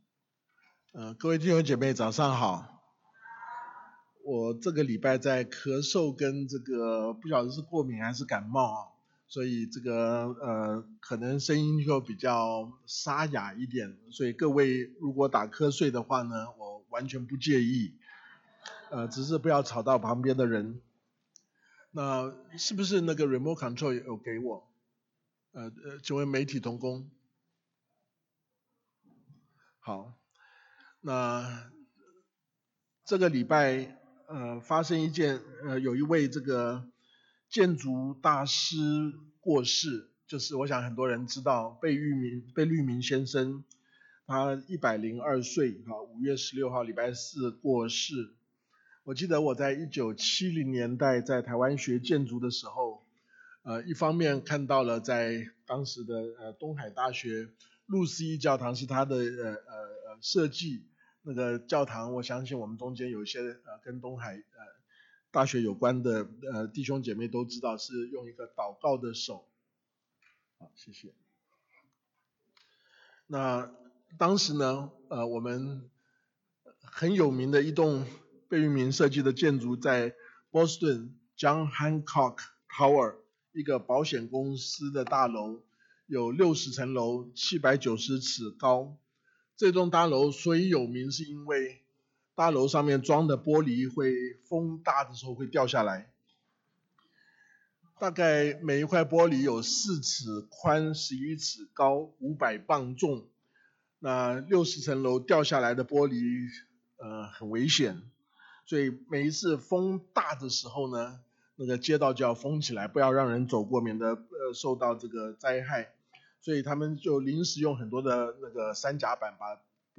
希伯来书 6:1-20 鼓励成长—从开端到完全 警戒退后—得福成为咒诅 忍耐坚持—信心爱心盼望 应许赏赐—上主起誓为证 永远帮助—先锋与大祭司 Audio Sermon